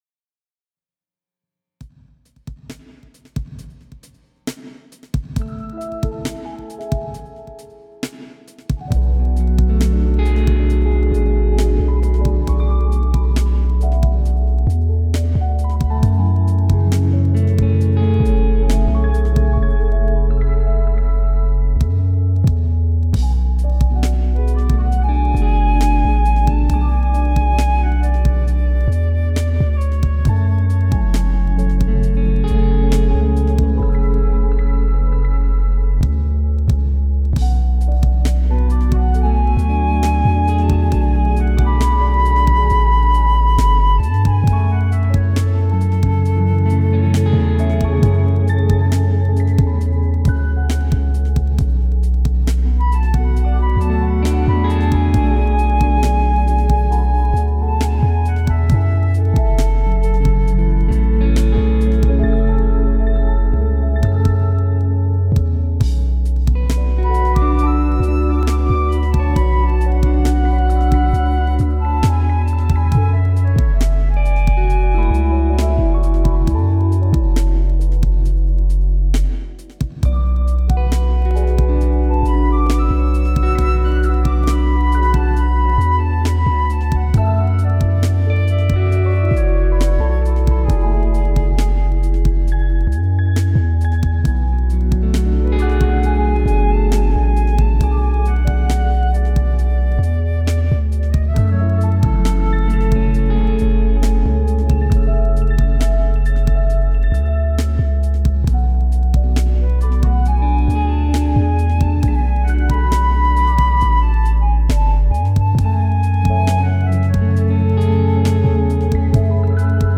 jazz-ambience.mp3